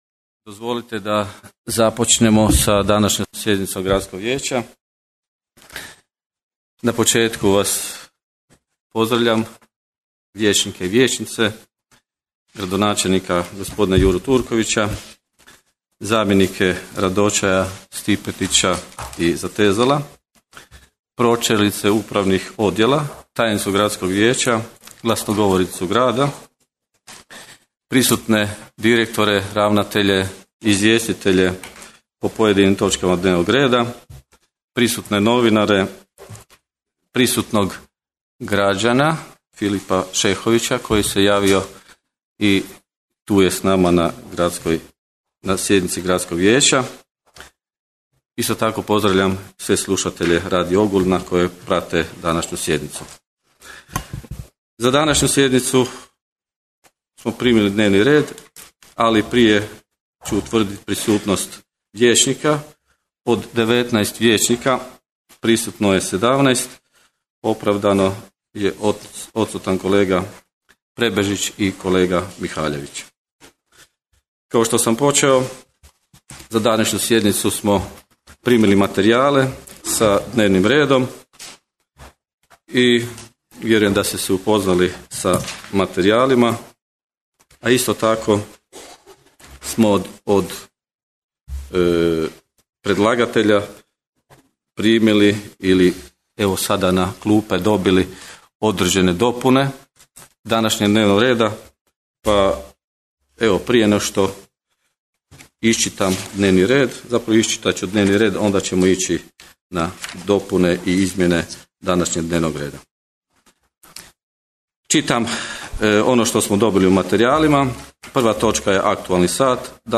Zaključci i odluke 24. sjednice Gradskog vijeća Grada Ogulina održane 11. travnja 2017. godine (utorak) u 13.00 sati u Velikoj dvorani Gradske knjižnice i čitaonice Ogulin, Bernardina Frankopana 7.